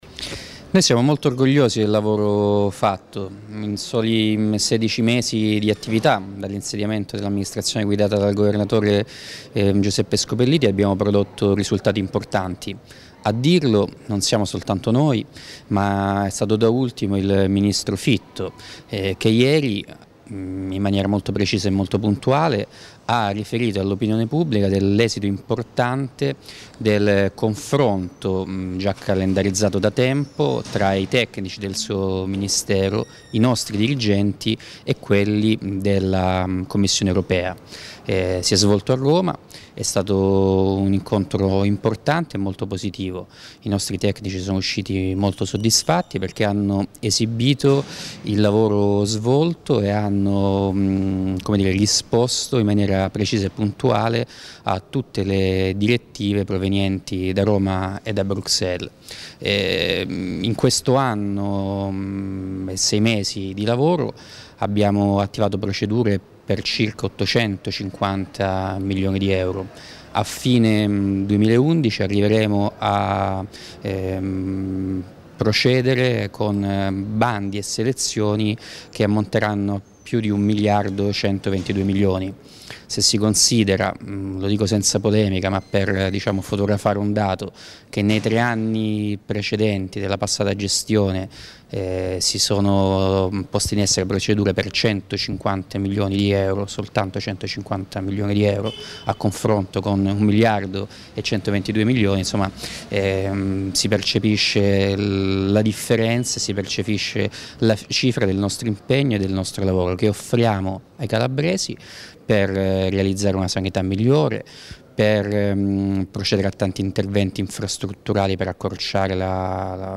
Ascolta L’INTERVISTA AUDIO DELL’ASSESSORE MANCINI